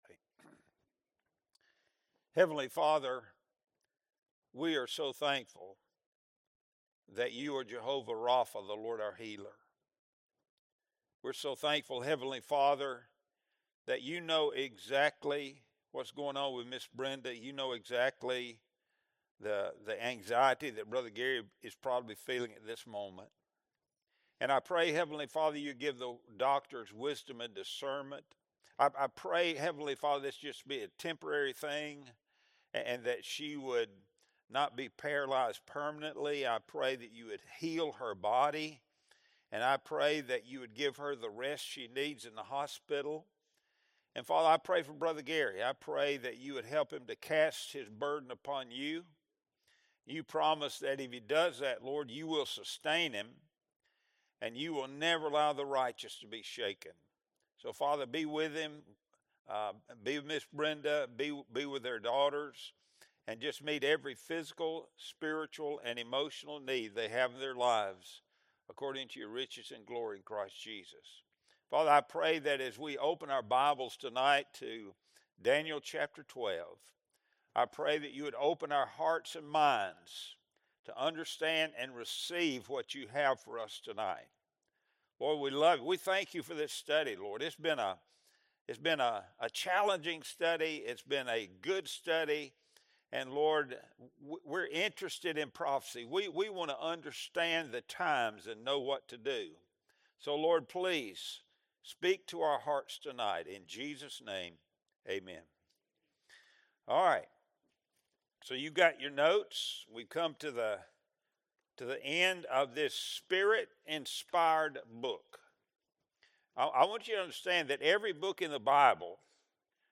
Wednesday Bible Study Series | February 25, 2026